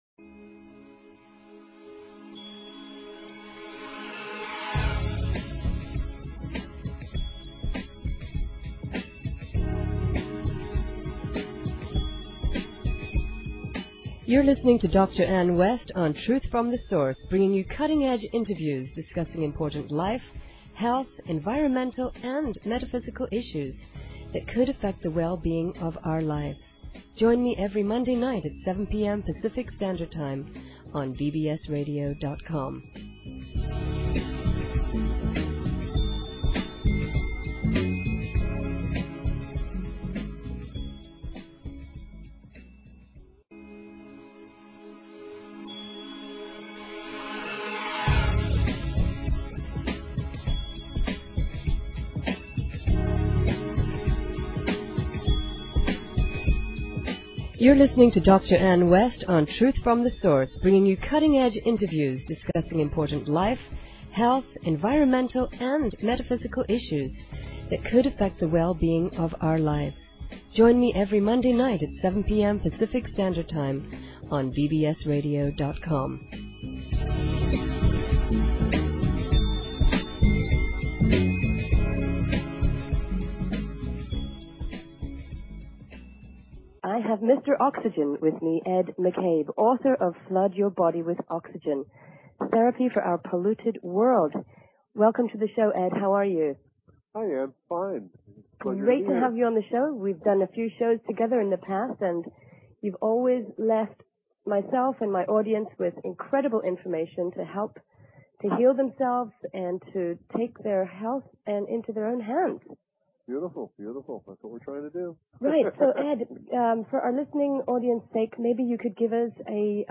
Talk Show Episode, Audio Podcast, Truth_From_Source and Courtesy of BBS Radio on , show guests , about , categorized as